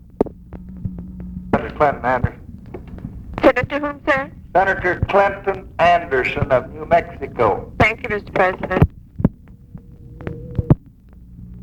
Conversation with TELEPHONE OPERATOR, February 3, 1964
Secret White House Tapes